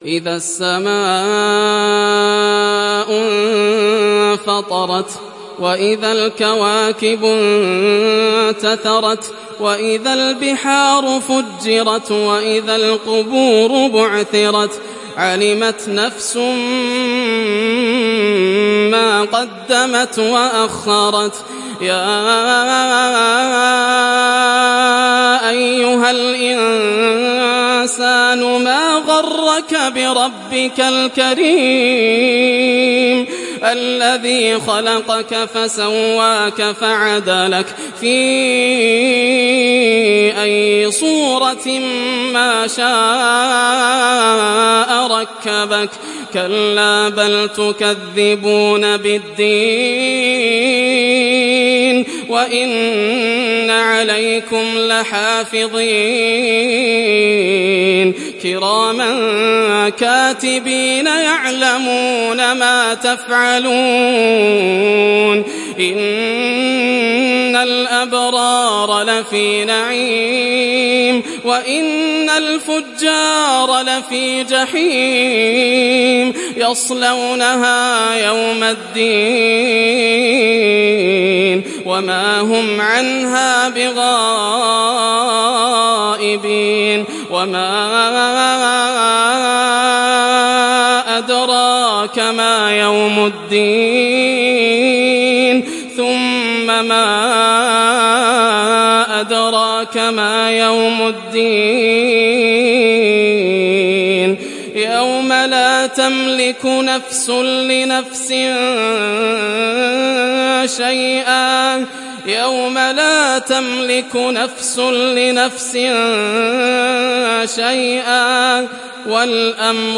Surat Al Infitar Download mp3 Yasser Al Dosari Riwayat Hafs dari Asim, Download Quran dan mendengarkan mp3 tautan langsung penuh